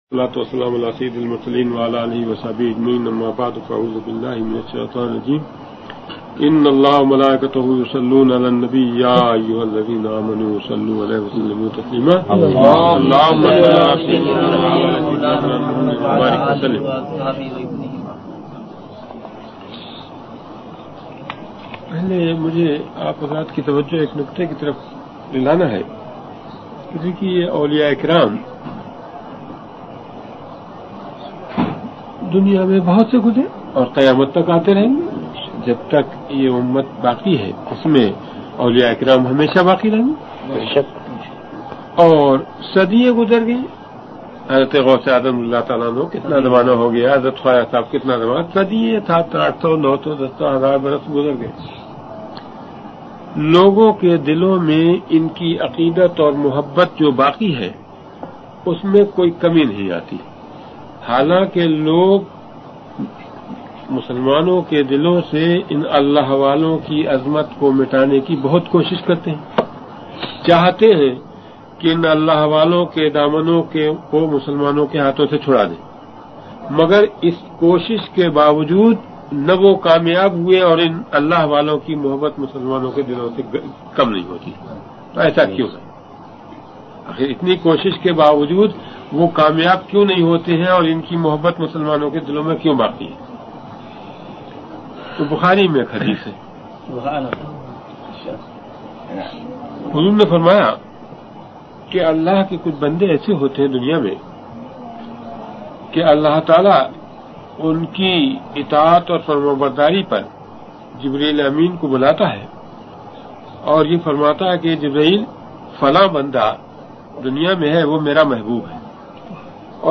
زمرہ جات : بیانات | زبان : اردو